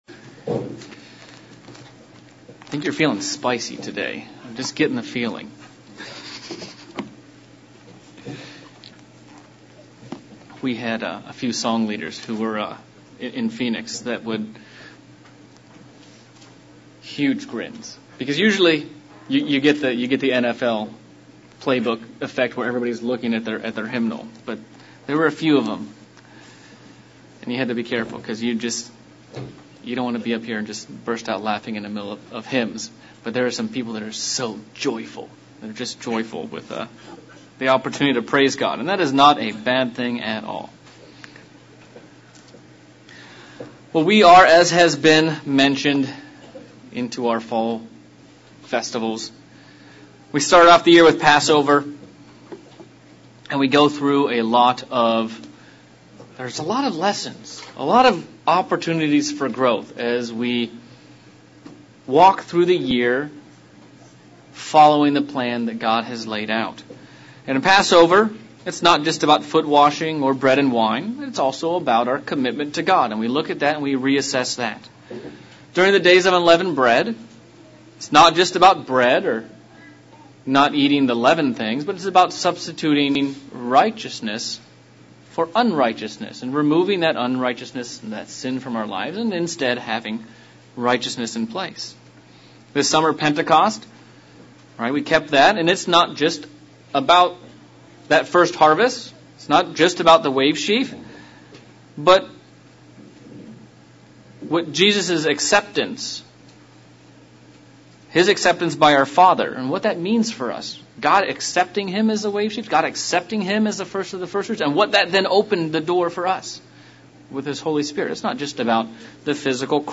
Pre Atonement sermon looking at the depth of this special Holy Day and how it is a matter of the heart and one we should deeply consider.